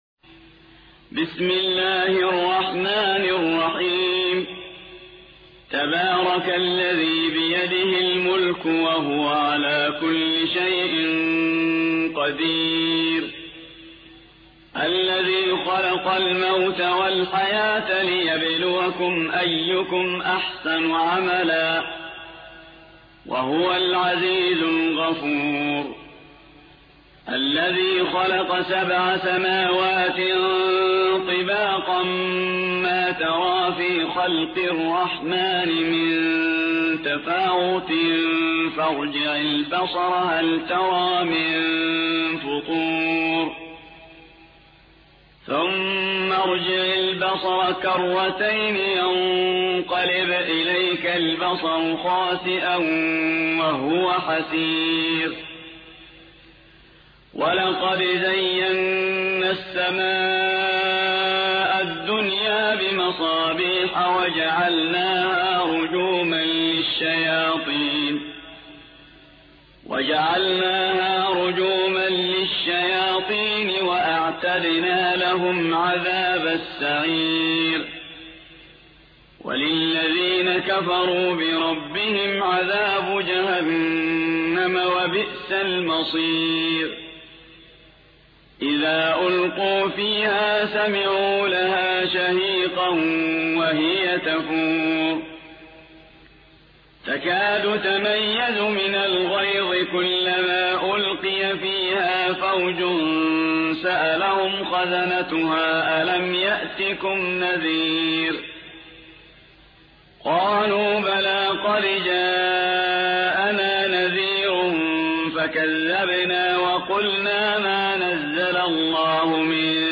67. سورة الملك / القارئ